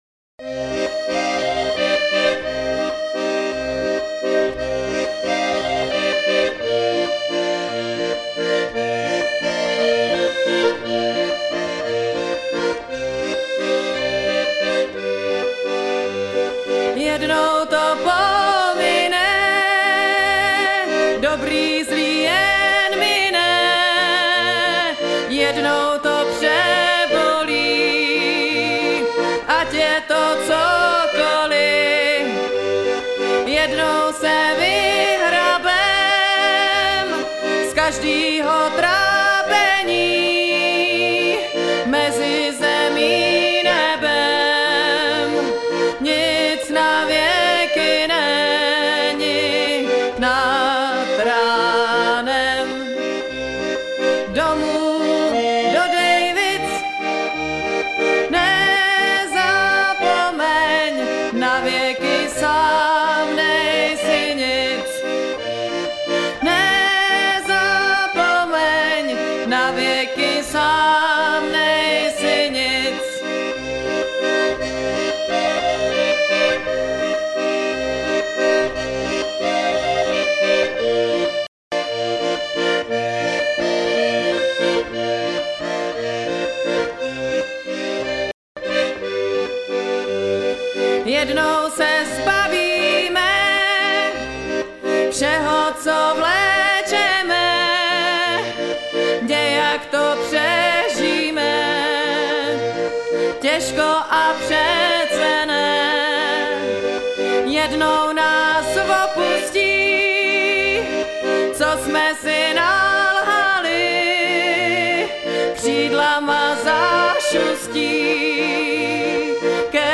" začíná tato písnička od známé zpěvačky s harmonikou ...